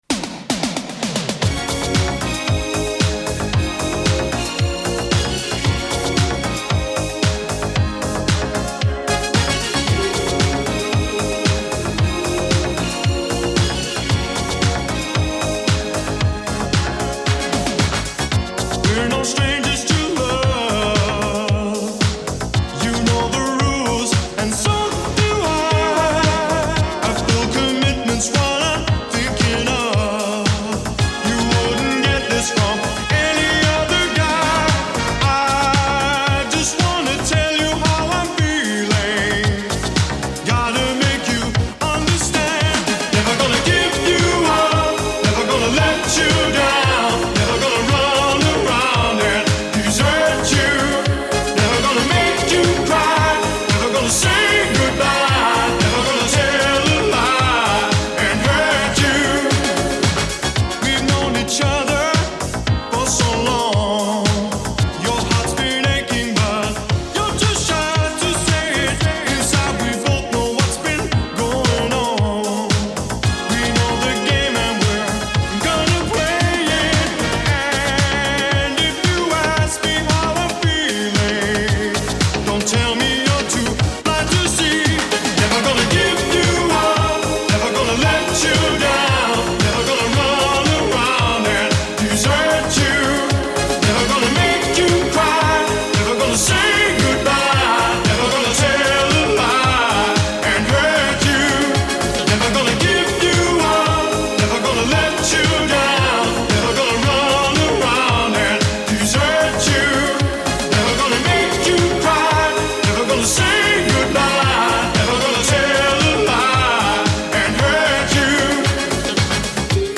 Количество каналов: 2
Видимо, в начале файла тишина.